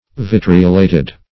Vitriolated \Vit"ri*o*la`ted\, a. (Old Chem.)